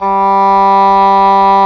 WND OBOE G3.wav